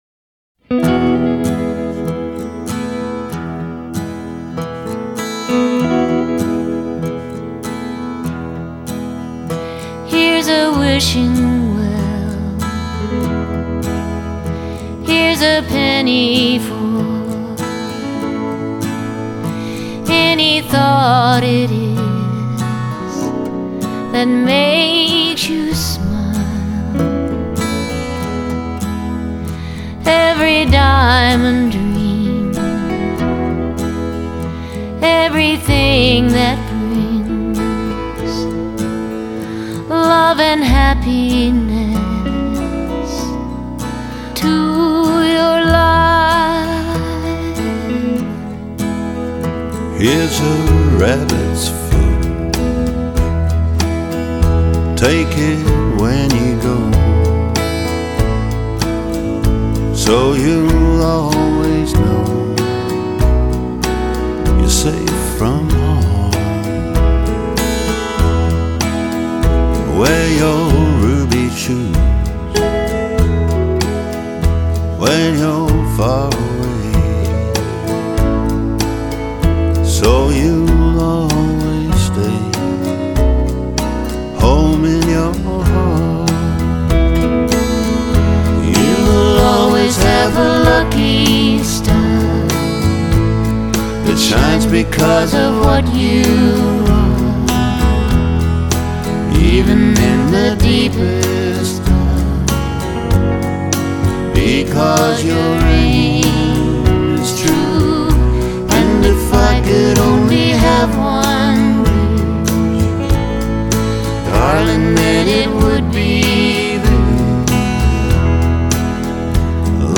唱片风格：Folk